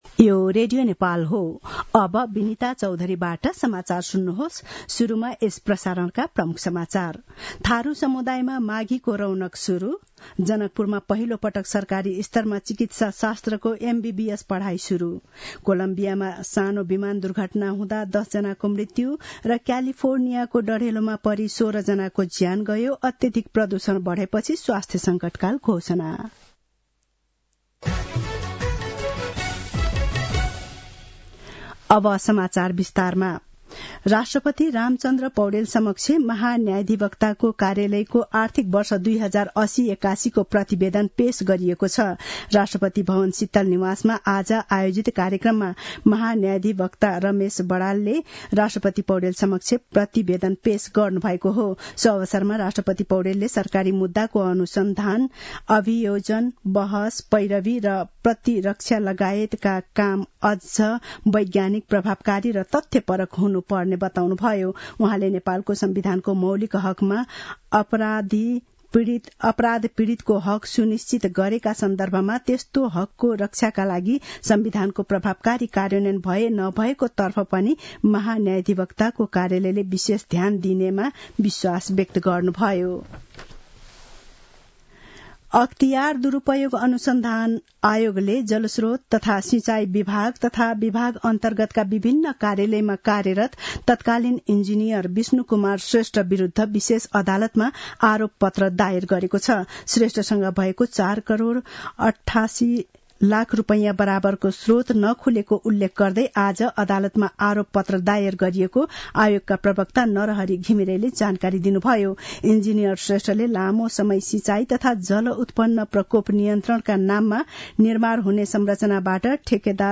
दिउँसो ३ बजेको नेपाली समाचार : २९ पुष , २०८१
3-pm-news.mp3